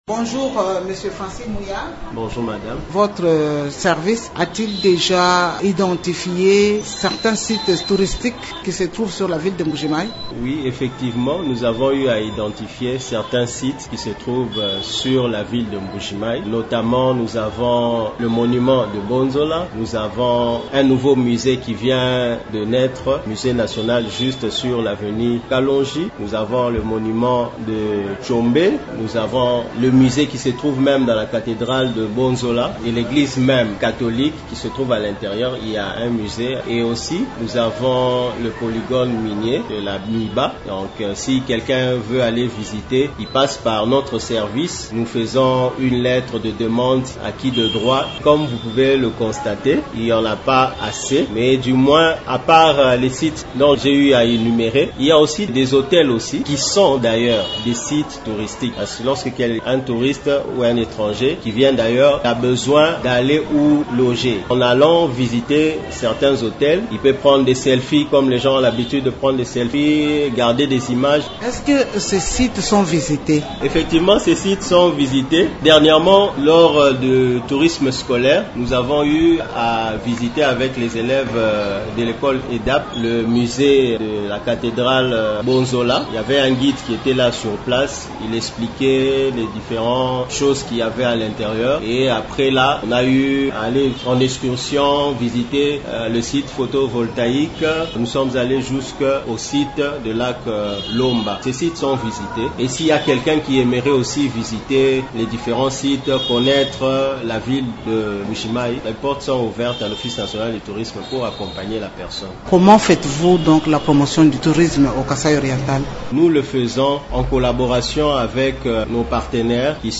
s’est entretenu avec